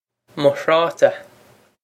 mo thráta muh hraw-ta
Pronunciation for how to say
This is an approximate phonetic pronunciation of the phrase.